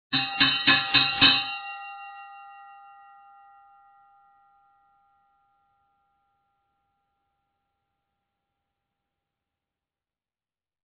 II. Bells
bell1.mp3